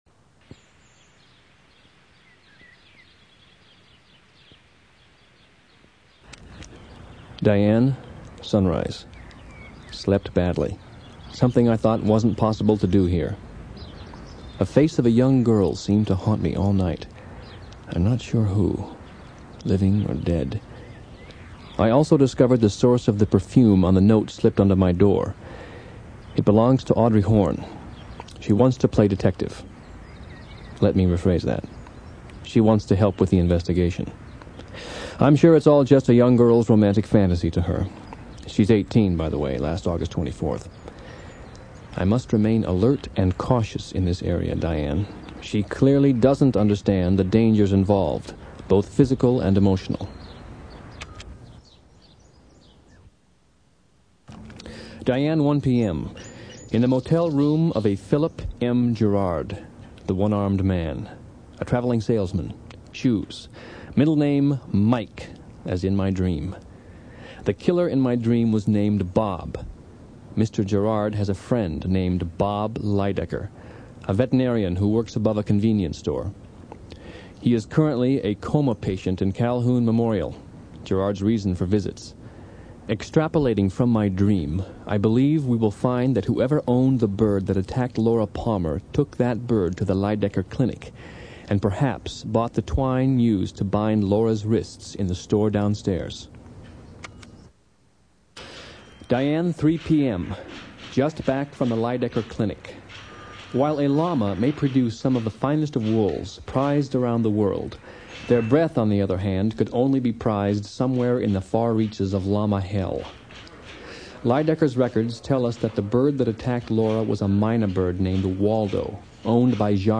They are audio cassettes taken partly from the series and partly afterwards. They chronicle his time in Twin Peaks, including all kinds of extra material. Always addressed to "Diane" and always interesting.